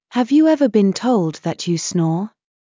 ﾊﾌﾞ ﾕｰ ｴｳﾞｧｰ ﾋﾞｰﾝ ﾄｰﾙﾄﾞ ｻﾞｯﾄ ﾕｰ ｽﾉｱ